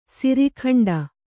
♪ siri khaṇḍe